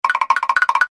Mr Krabs Walking Sound